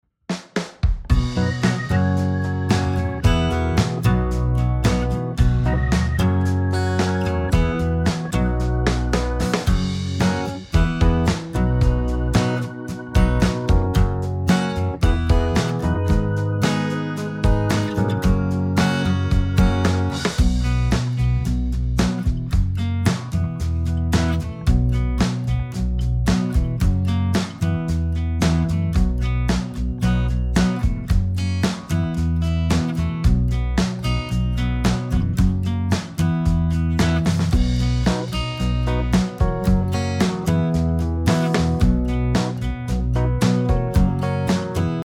Listen to a sample of the sing-along track.